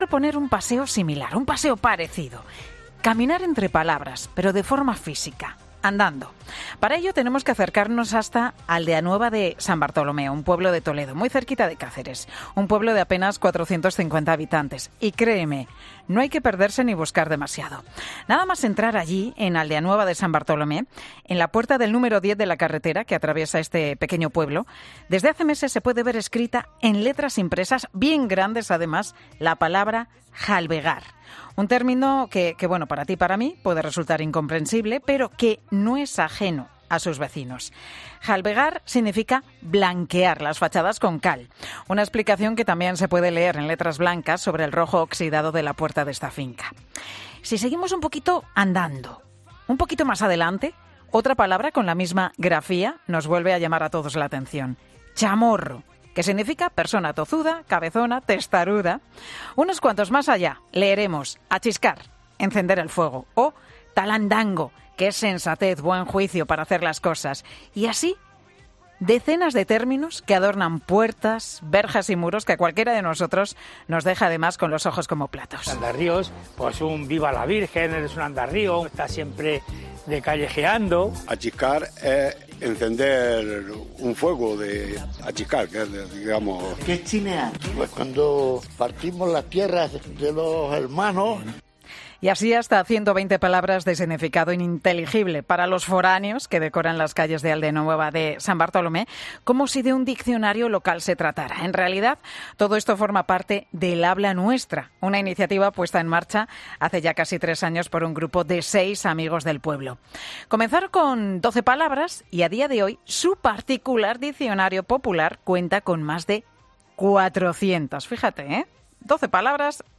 AUDIO: Escucha aquí la entrevista a un vecino de un pueblo toledano explicando la última iniciativa del municipio